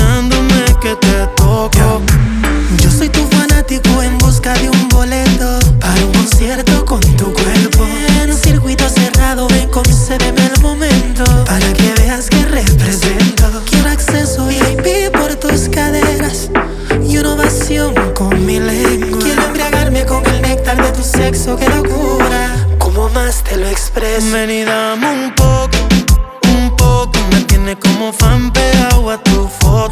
• Urbano latino